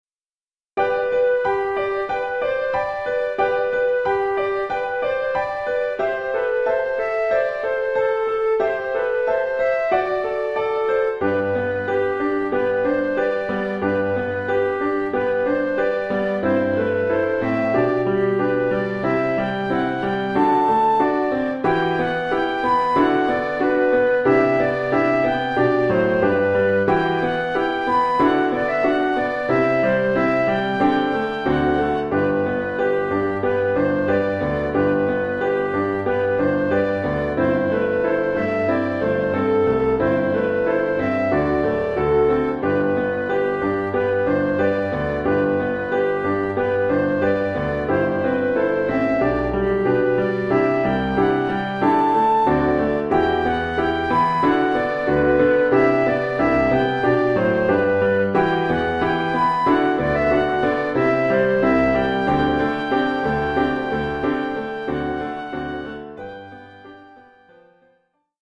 1 titre, flûte et piano : conducteur et partie de flûte ut
Oeuvre pour flûte et piano.
Niveau : débutant.